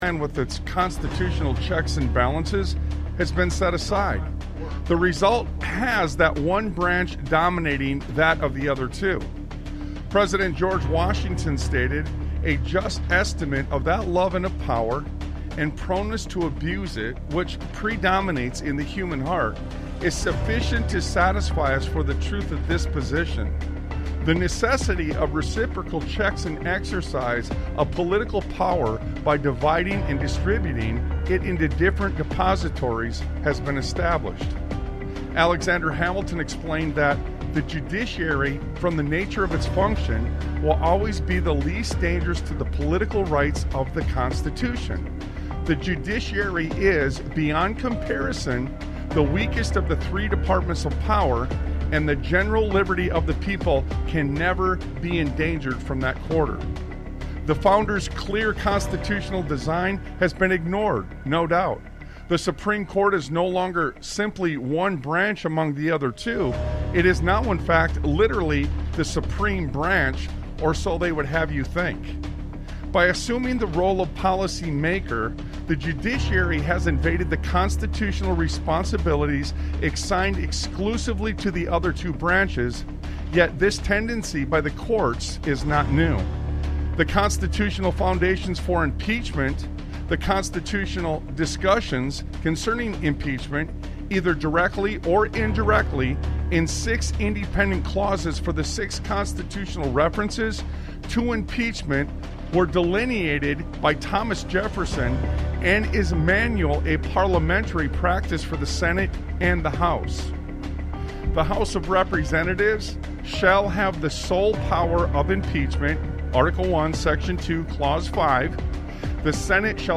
Talk Show Episode, Audio Podcast, Sons of Liberty Radio and What Is Dangerous Here Is What The People Tolerate on , show guests , about What Is Dangerous Here Is What The People Tolerate, categorized as Education,History,Military,News,Politics & Government,Religion,Christianity,Society and Culture,Theory & Conspiracy